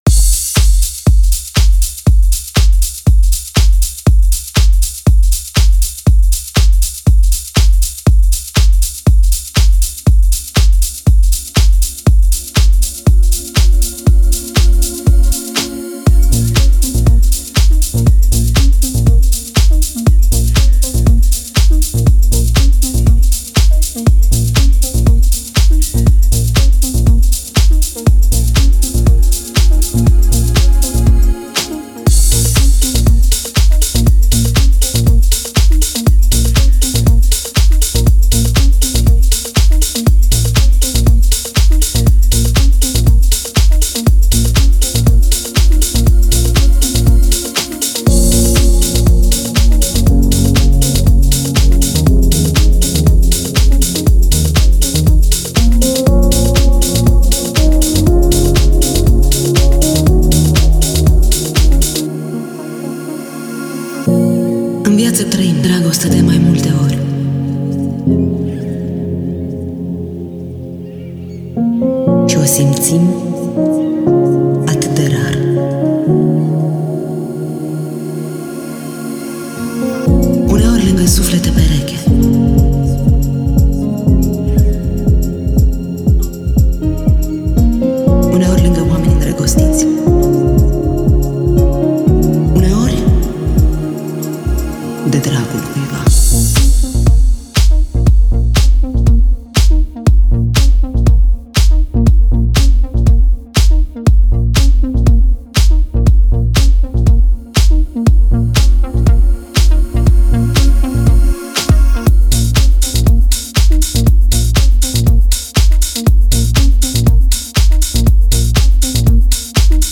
это романтическая композиция в жанре поп